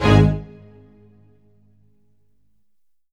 ORCHHIT G09R.wav